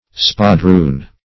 Search Result for " spadroon" : The Collaborative International Dictionary of English v.0.48: Spadroon \Spa*droon"\, n. [Cf. F. & Sp. espadon, It. spadone.